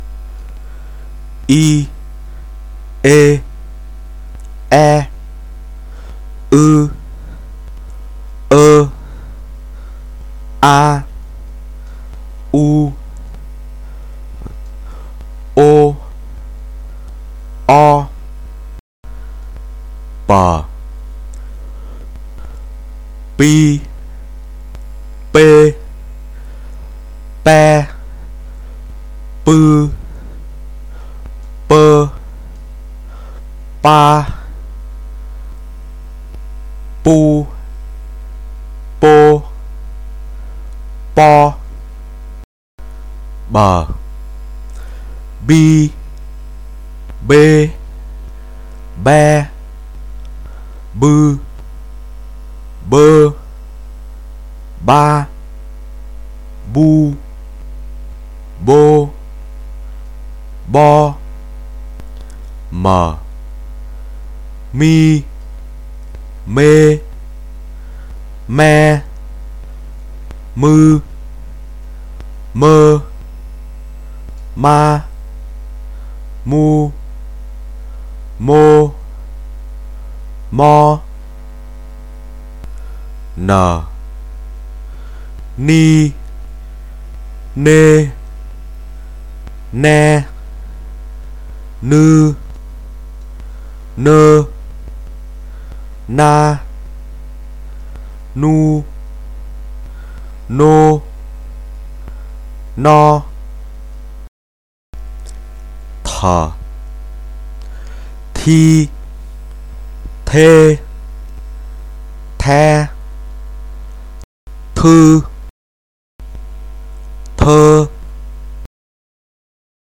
s 按照 x 的发音来练习，以免之后需要调整。
与声母 x s 的情况类似，在这里，我们也将声母 gi r 按照声母 d 的发音来练习。